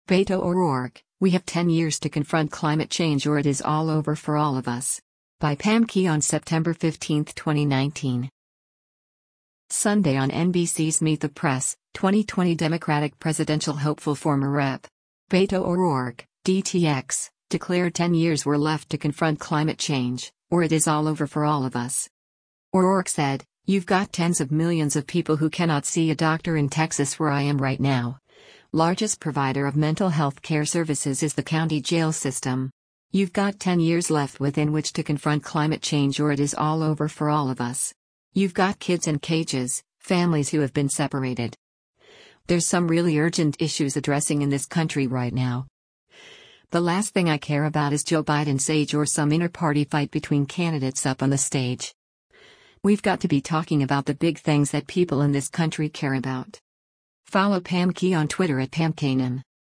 Sunday on NBC’s “Meet the Press,” 2020 Democratic presidential hopeful former Rep. Beto O’Rourke (D-TX) declared 10 years were left to confront climate change, “or it is all over for all of us.”